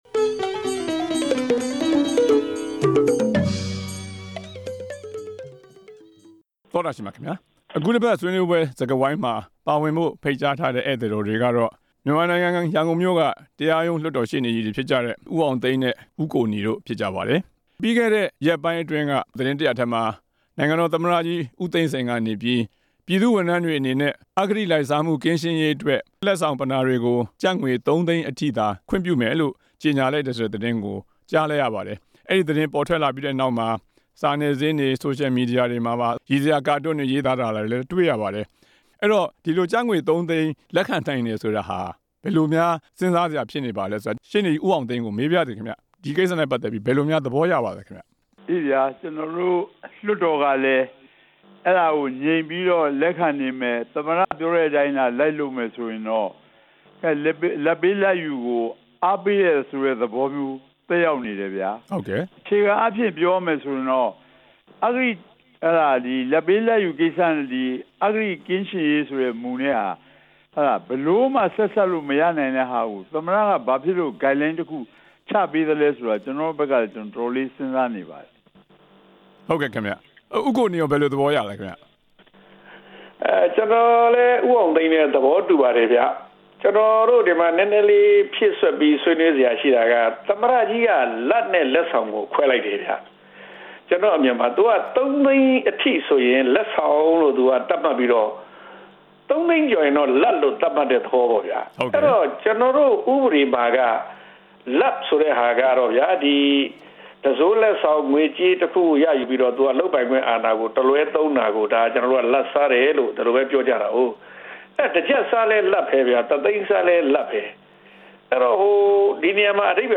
အစိုးရ အဖွဲ့ဝင်များ ကျပ်သုံးသိန်းကျော် လက်ဆောင်ပစ္စည်း မယူရေး ဆွေးနွေးချက်